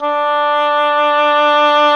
WND OBOE2 01.wav